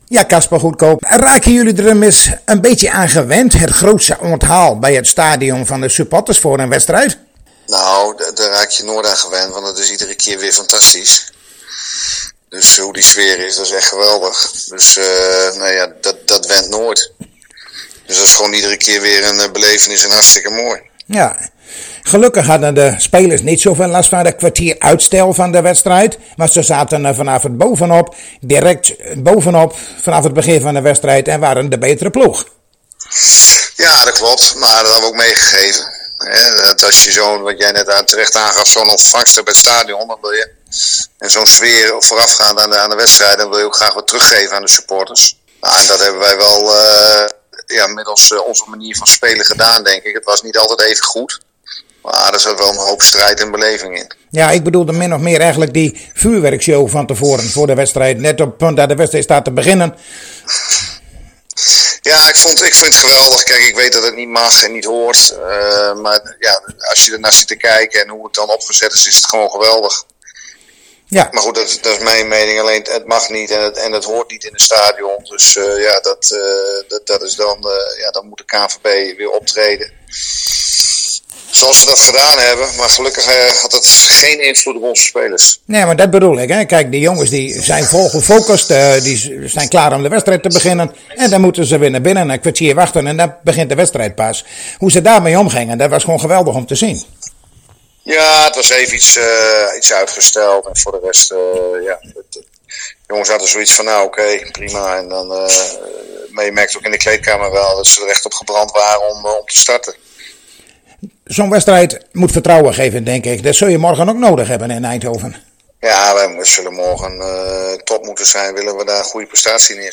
In gesprek